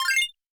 Coins (17).wav